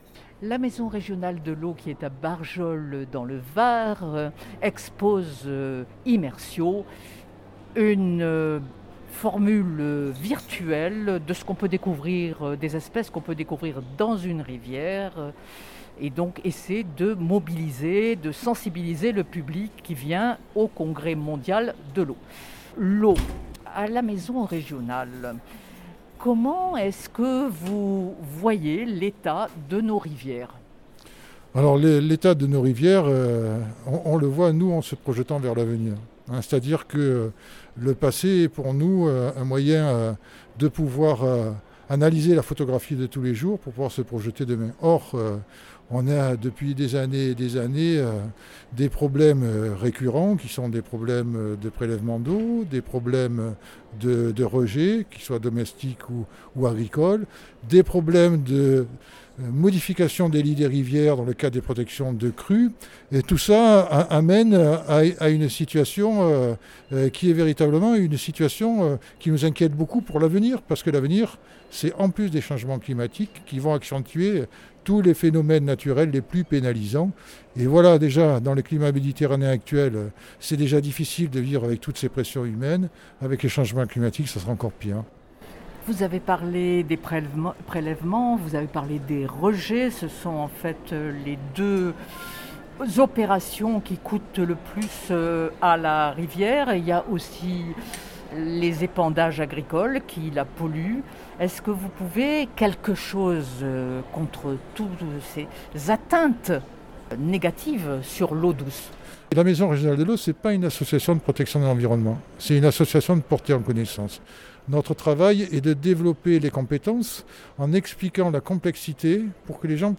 Entretien.